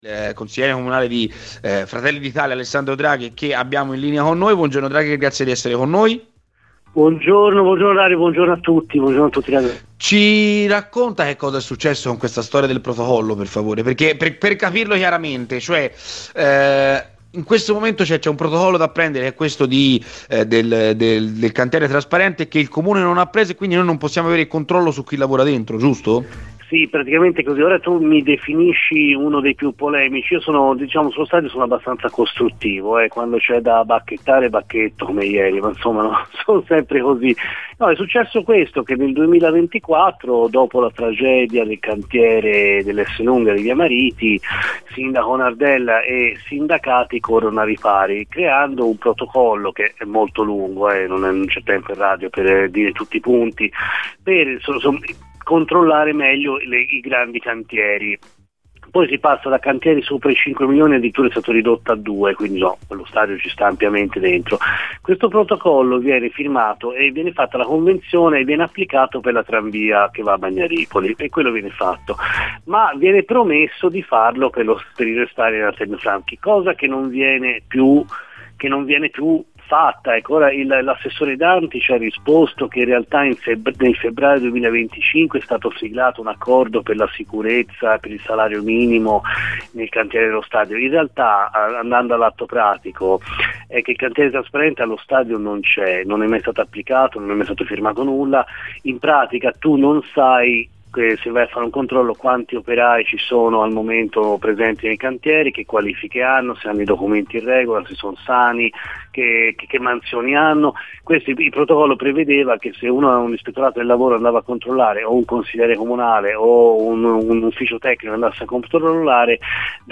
Nella mattinata di Radio FirenzeViola, durante la trasmissione 'C'è Polemica' per parlare della situazione dello stadio Franchi è intervenuto Alessandro Draghi, Consigliere Comunale di Fratelli d'Italia.